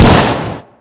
sol_reklam_link sag_reklam_link Warrock Oyun Dosyalar� Ana Sayfa > Sound > Weapons > DesertEagle Dosya Ad� Boyutu Son D�zenleme ..
WR_fire.wav